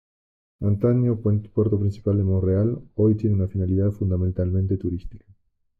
Read more purpose, objective, aim Frequency 30k Hyphenated as fi‧na‧li‧dad Pronounced as (IPA) /finaliˈdad/ Etymology From Latin fīnālitātem.